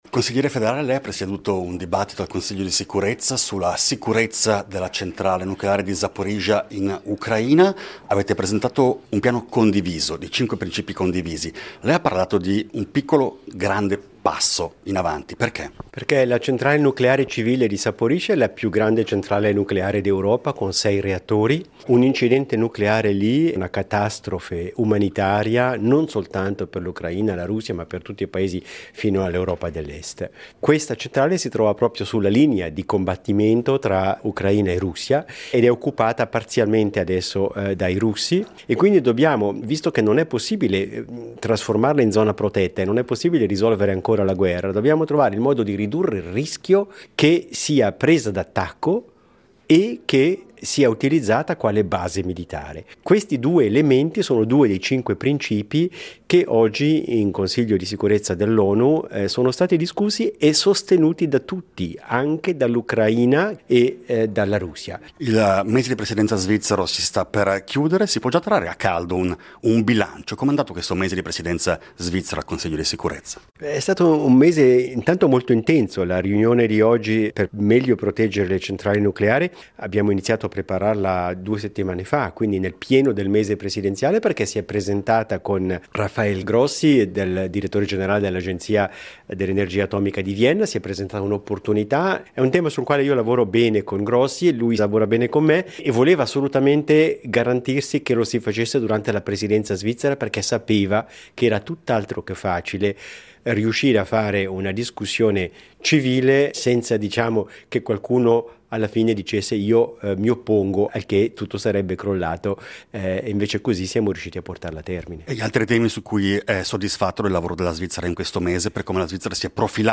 Intervista a Ignazio Cassis